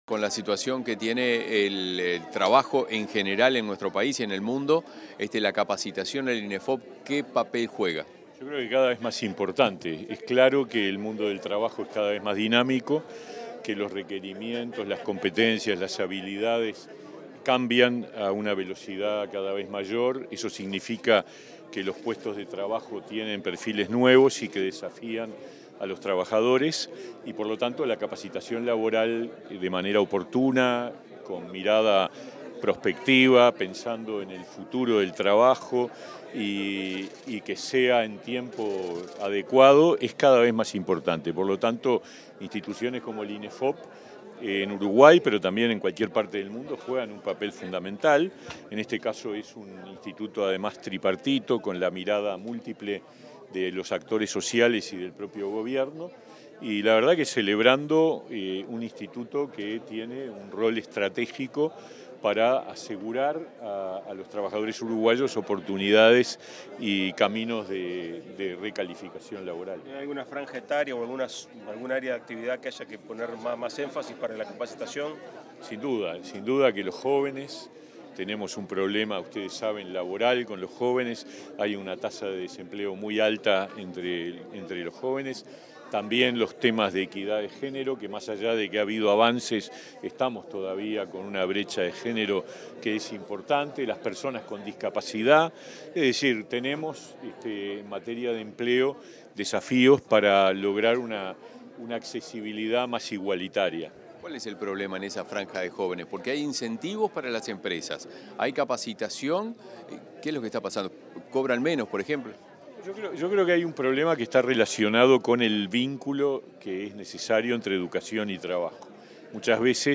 Declaraciones del ministro de Trabajo y Seguridad Social, Pablo Mieres
Declaraciones del ministro de Trabajo y Seguridad Social, Pablo Mieres 24/10/2023 Compartir Facebook X Copiar enlace WhatsApp LinkedIn Tras participar en la celebración de los 15 años del Instituto Nacional de Empleo y Formación Profesional (Inefop), este 24 de octubre, el ministro de Trabajo y Seguridad Social, Pablo Mieres, realizó declaraciones a la prensa.